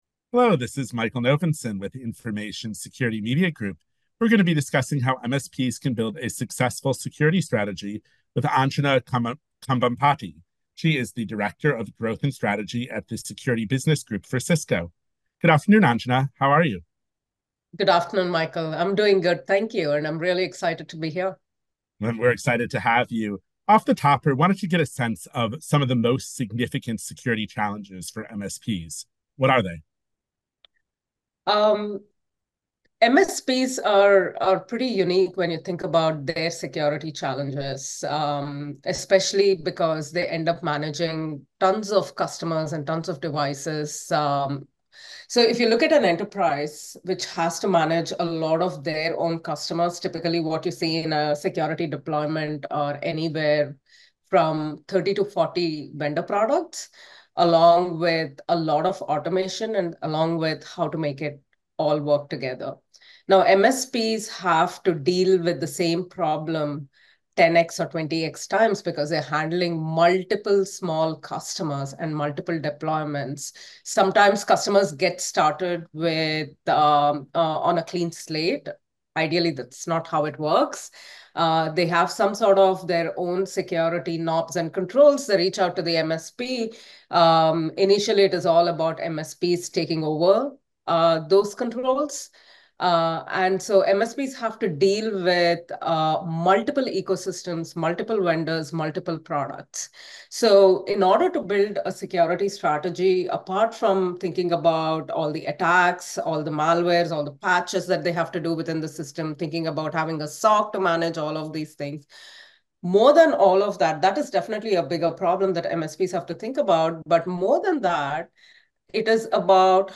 Exclusive, insightful audio interviews by our staff with banking/security leading practitioners and